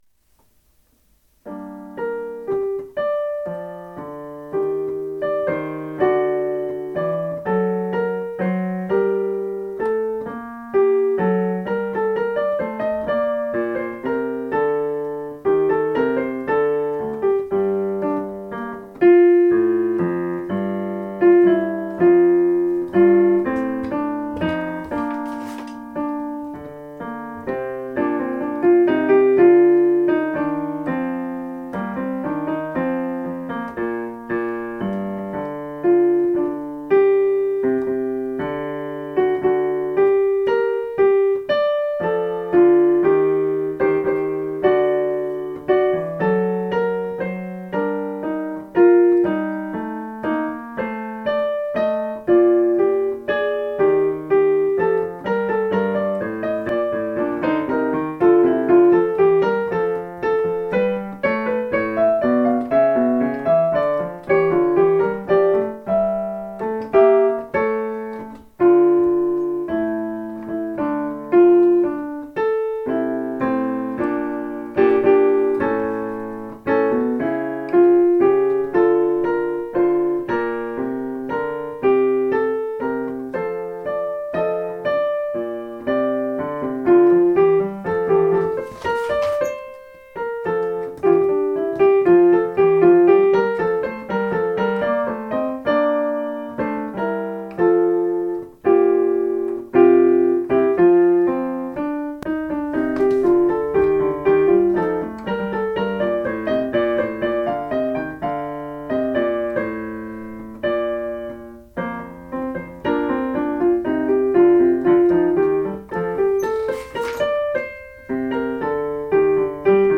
Kammerchor
Aufnahmen zum Üben